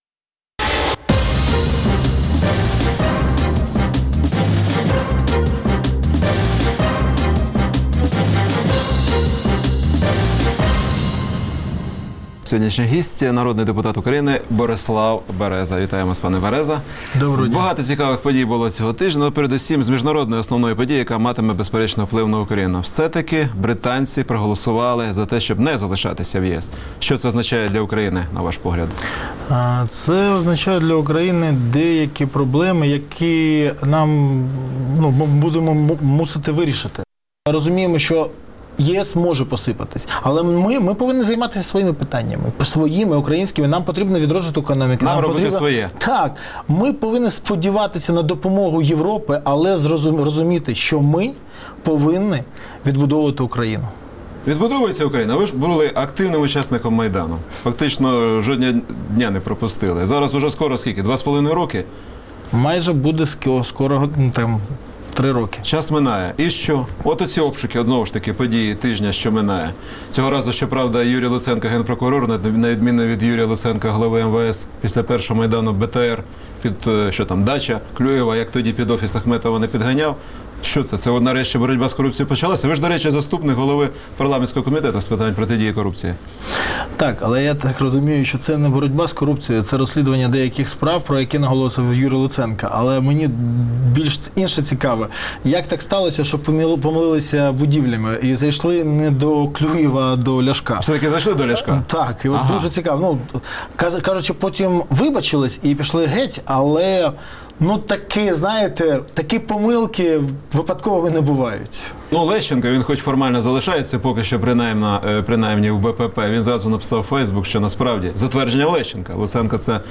Генпрокурор Луценко здатен боротися з корупцією не лише на словах. У цьому переконував в інтерв’ю Радіо Свобода заступник голови парламентського комітету з протидії корупції Борислав Береза, коментуючи обшуки у приміщеннях, що належать посадовцям часів президента Януковича.